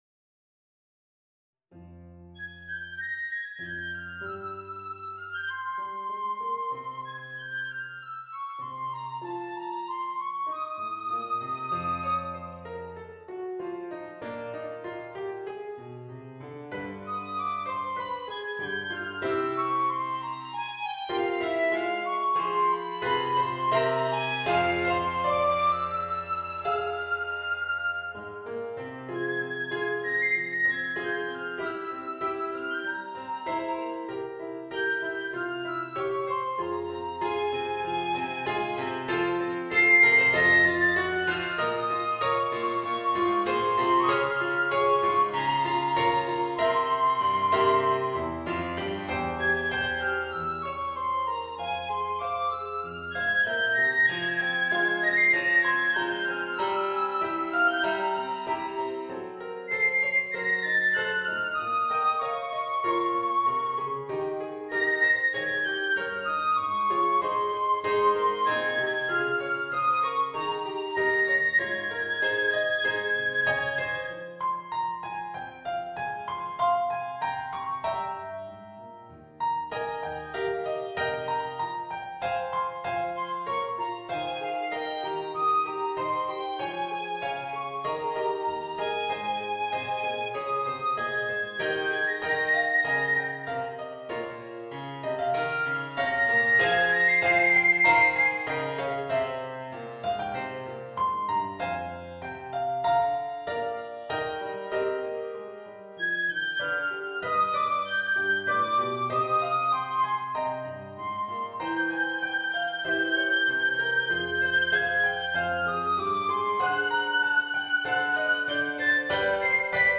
Voicing: Piccolo and Piano